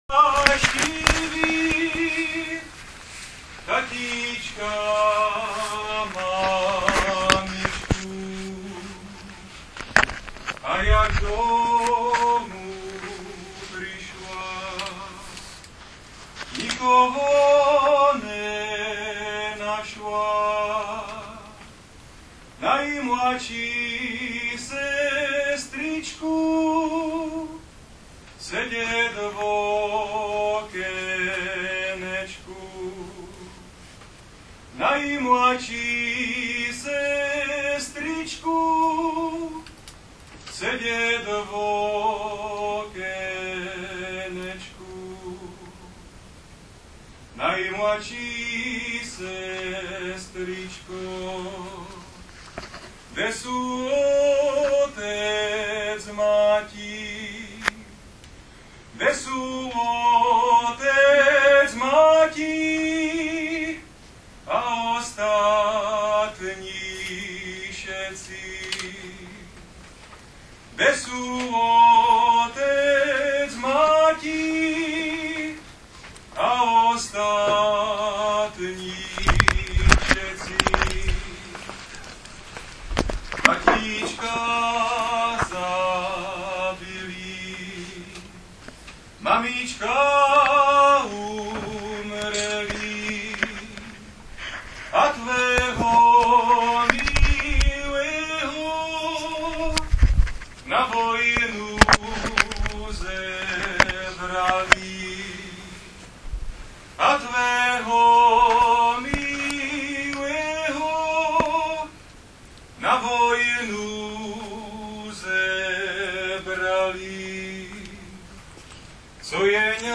Promiňte mi tento úvod, sobotní setkání na Vápenkách patřilo mezi mými "poštovním zastaveními" k těm nejkrásnějším (opět slovo krása, omlouvám se) v životě a velmi pěkně navazovalo na setkání v Javorníku z minulého týdne - zvukový záznam z něho je také tady na webu.
Vápenky, hostinec